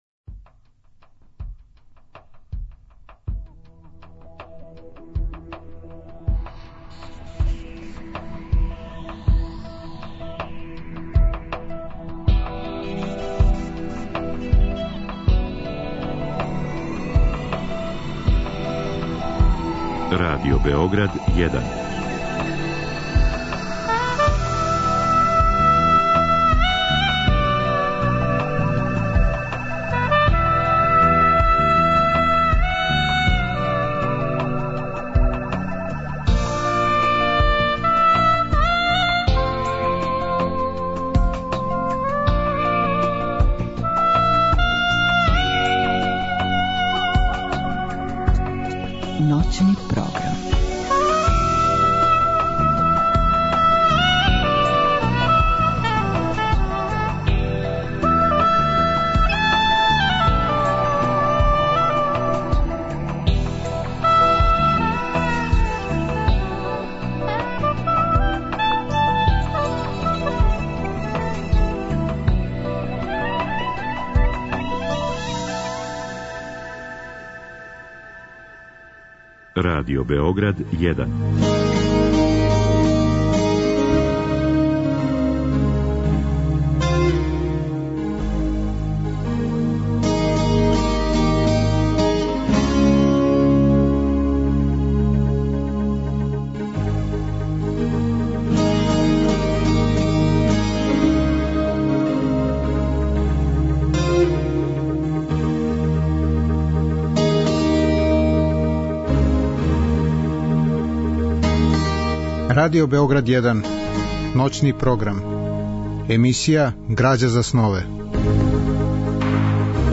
У другом делу емисије, од два до четири часa ујутро, слушаћемо одабране делове из "Kројцерове сонате" Лава Николајевича Толстоја, реализоване у форми аудио-књиге.